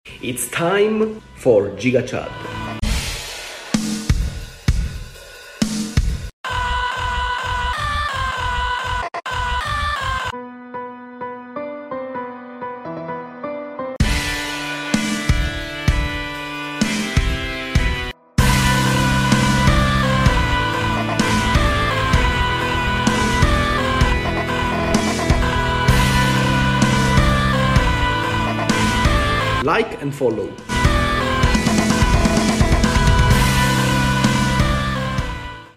giga chad beat